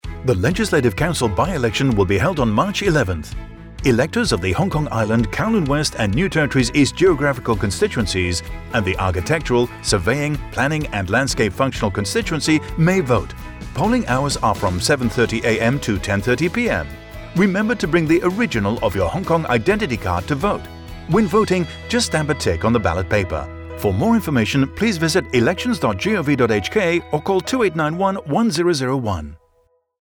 Radio Announcement